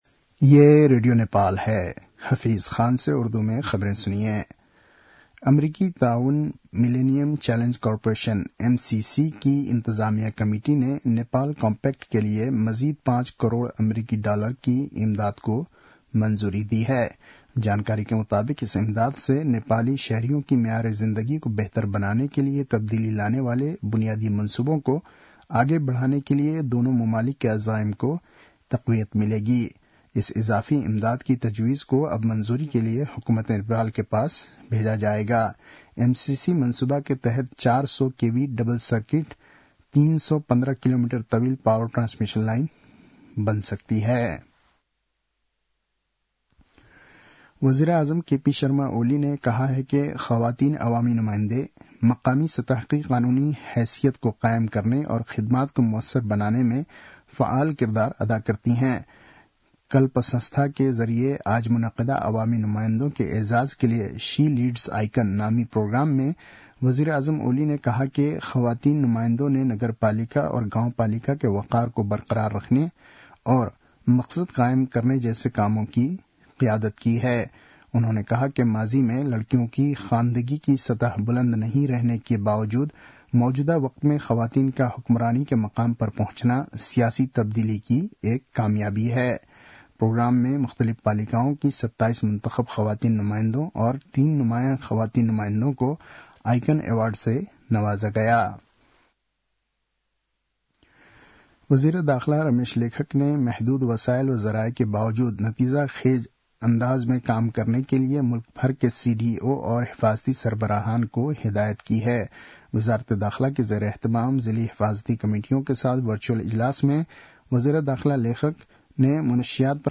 उर्दु भाषामा समाचार : २० पुष , २०८१